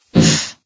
CitadelStationBot df15bbe0f0 [MIRROR] New & Fixed AI VOX Sound Files ( #6003 ) ...
oof.ogg